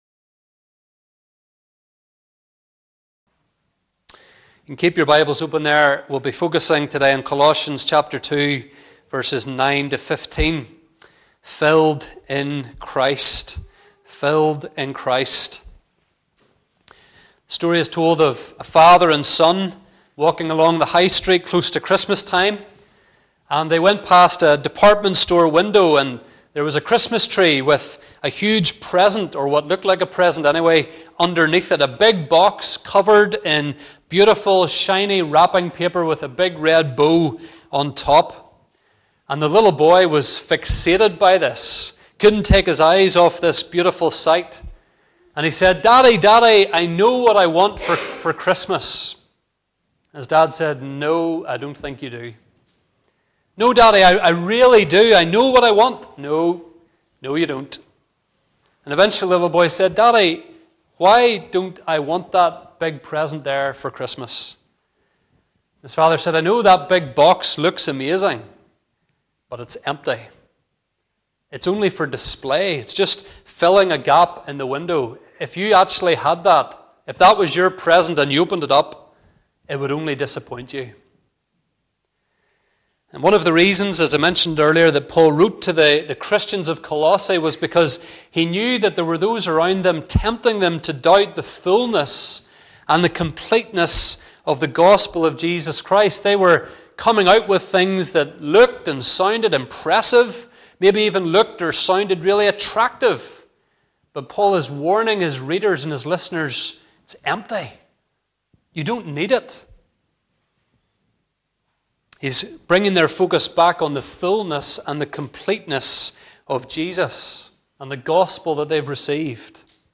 Dromore RP Church - Sermons Podcast - Filled in Christ - Colossians 2:9-15 | Free Listening on Podbean App